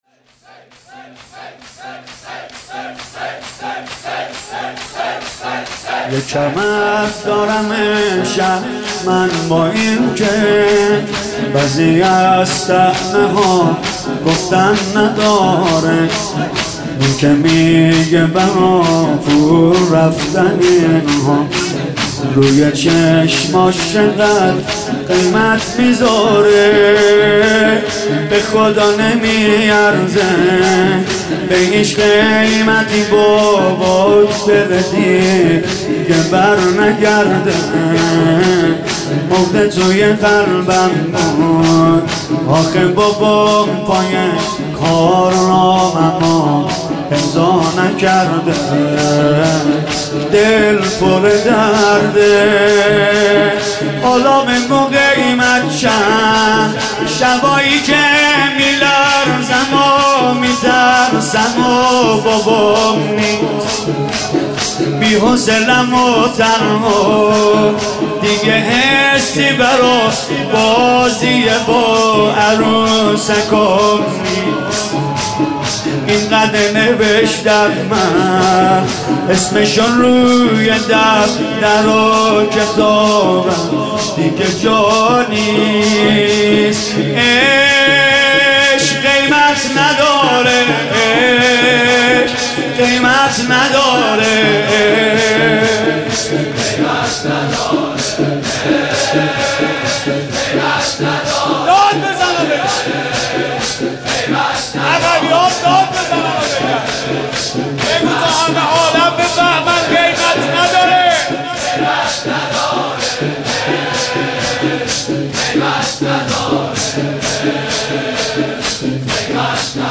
مداحی و سینه زنی